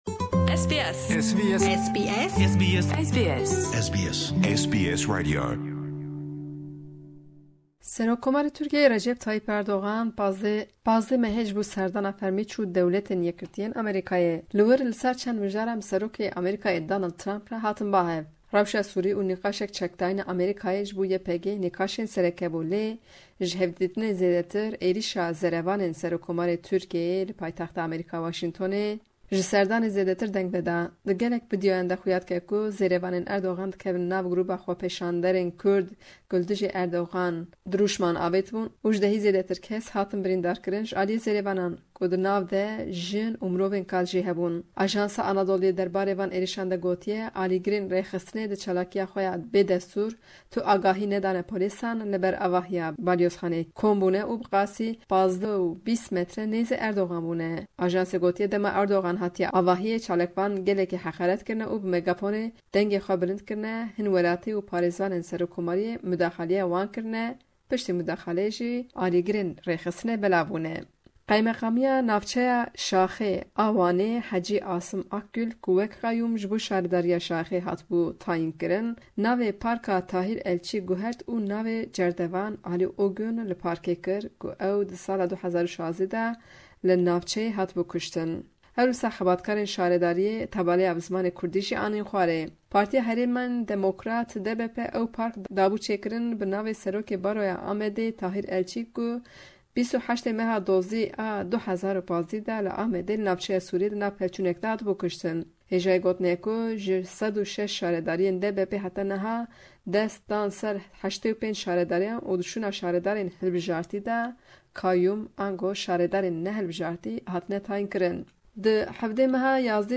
Ji Amed ra raporta